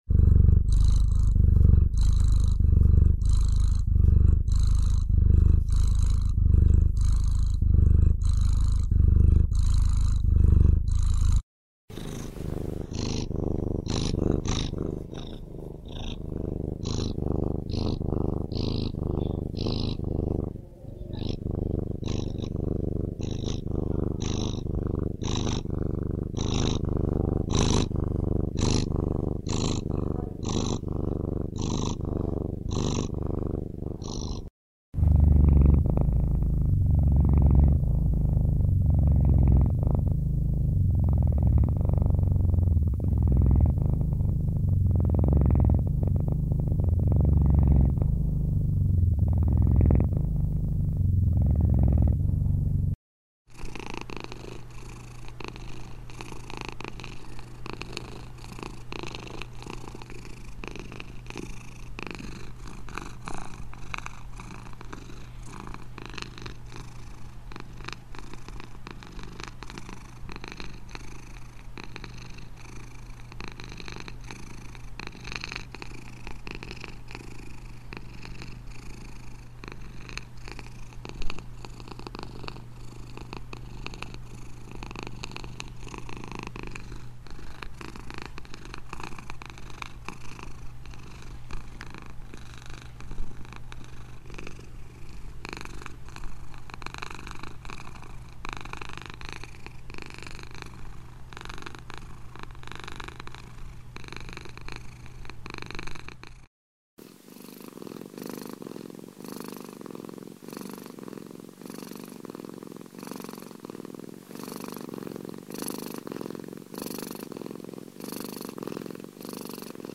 Кошачье мурлыканье {-29-}, четырнадцать вариантов! Очень успокаивающие звуки!